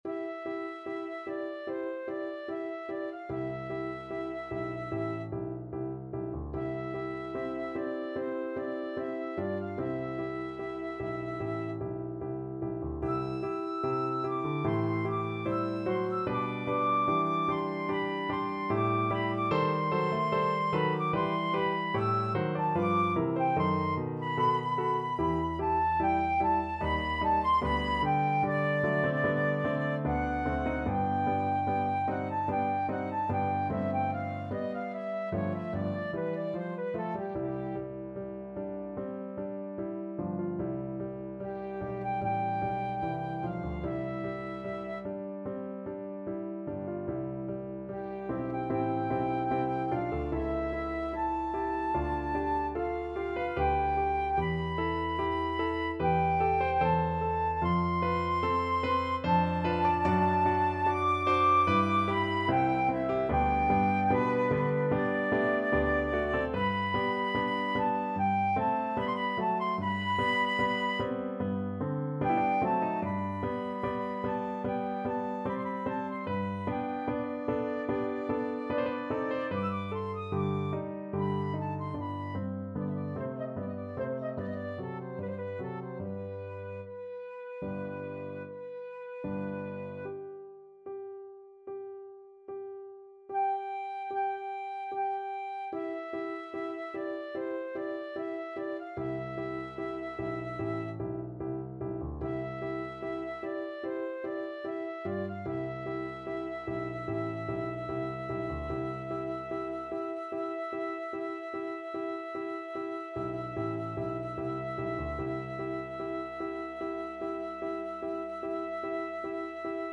Flute
C major (Sounding Pitch) (View more C major Music for Flute )
~ = 74 Moderato
Classical (View more Classical Flute Music)